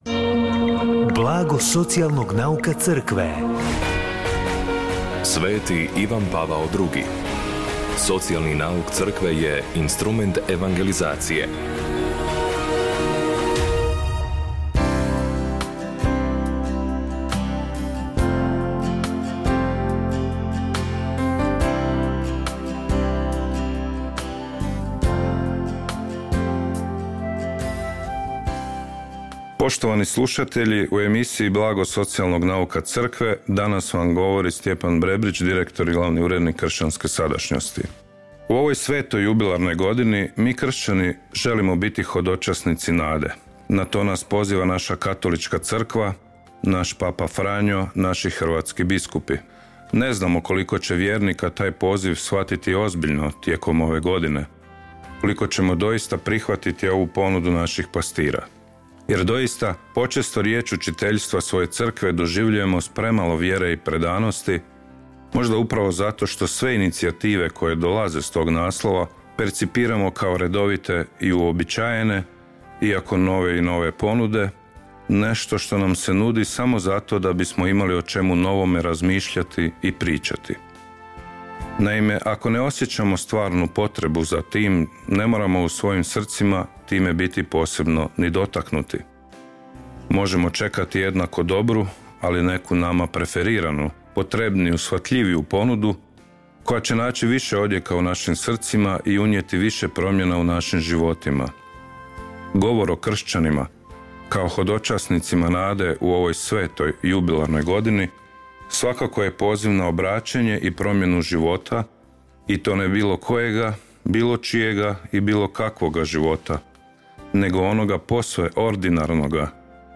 Emisiju na valovima HKR-a „Blago socijalnog nauka Crkve“ srijedom u 16:30 emitiramo u suradnji s Centrom za promicanje socijalnog nauka Crkve Hrvatske biskupske konferencije.